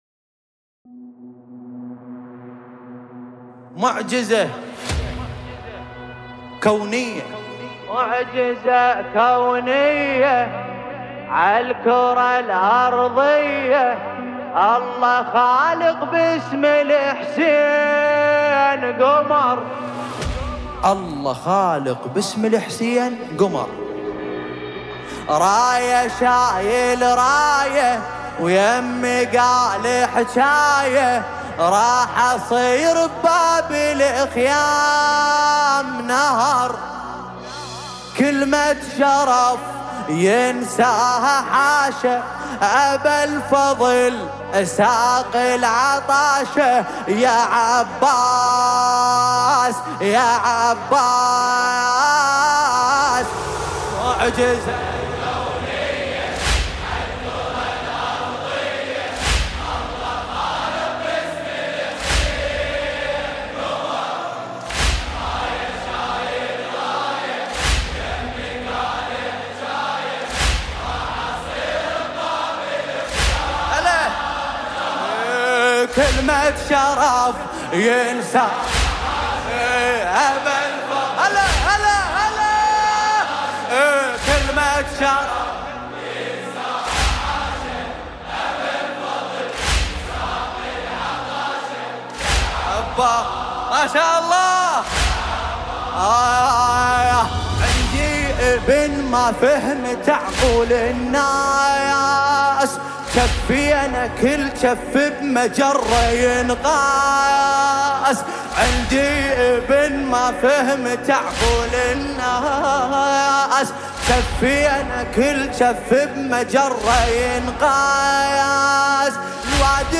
الرادود